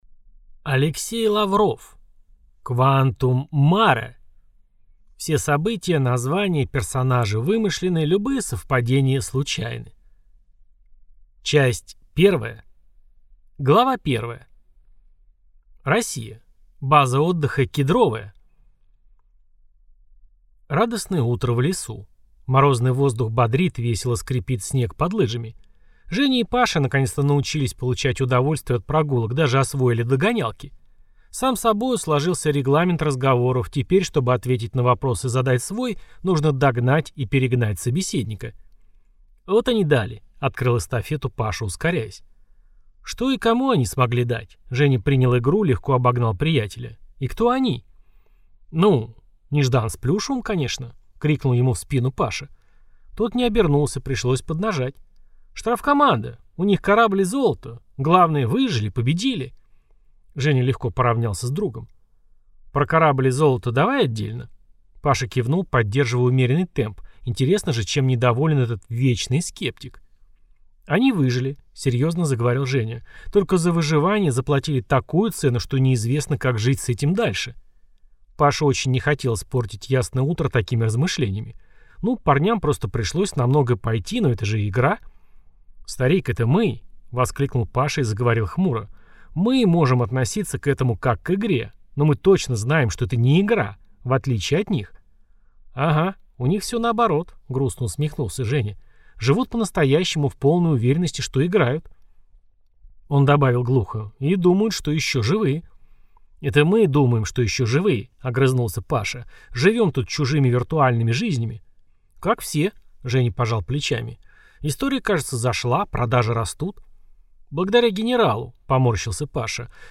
Аудиокнига Quantum Mare | Библиотека аудиокниг